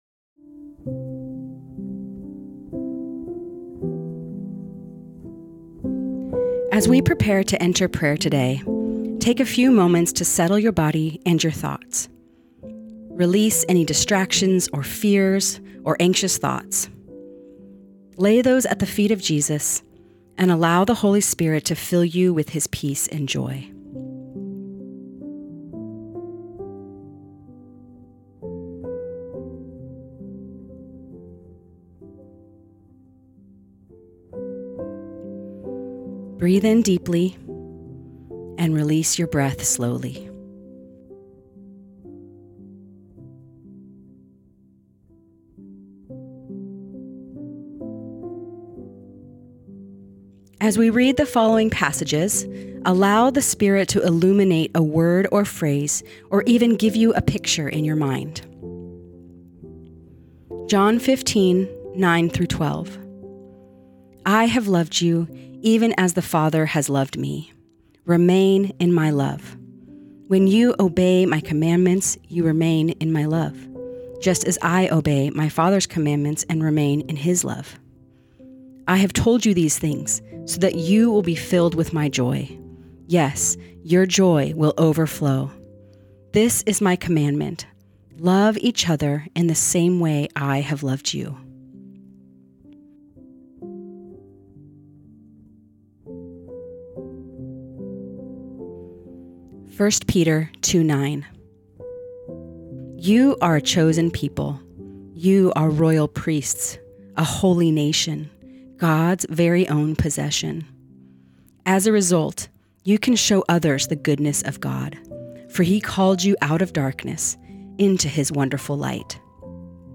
Guided Listening Practice